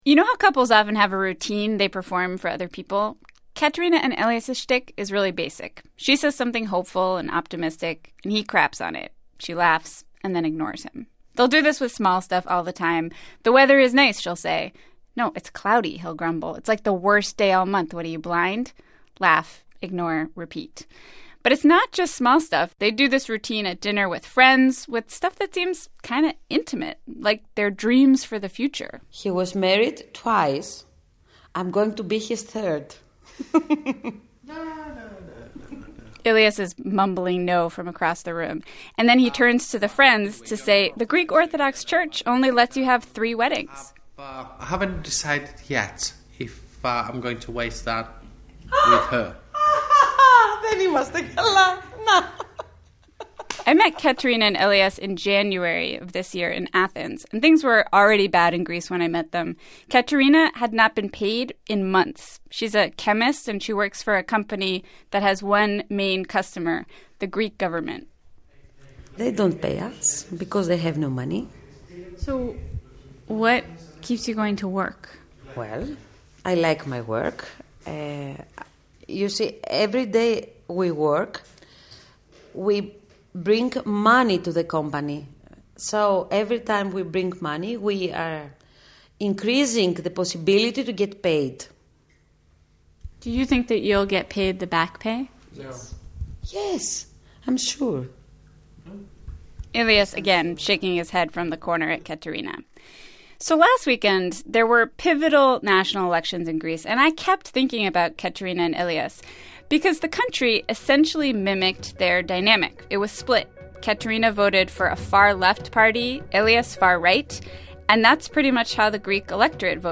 What if that turns out that the pain and suffering is a 20-year detour detour into economic collapse?On today's show, we hear from a Greek economist about the options open to the country. And we talk to a Greek couple that's getting married tomorrow. They're not sure how they're going to pay for the wedding.